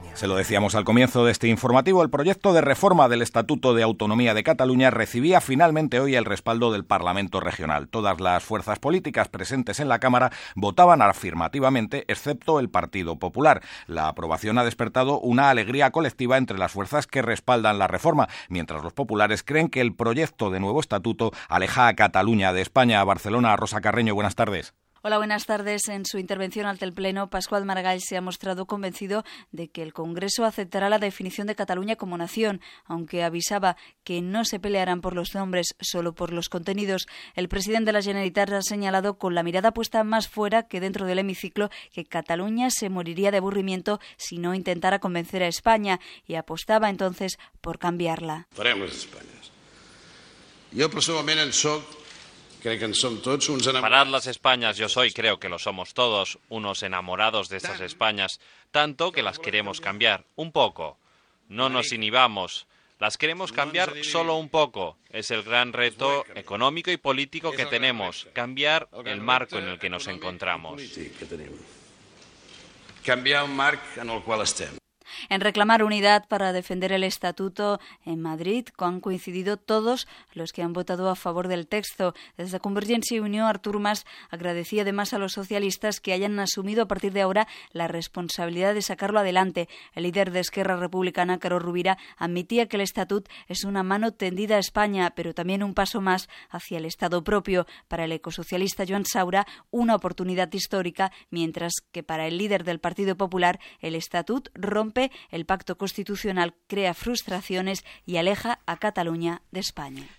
El Parlament de Catalunya aprova el text de Nou Estatut d'Autonomia. Paraules del president de la Generalitat Pasqual Maragall i resum de valoracions dels líders dels partits polítics
Informatiu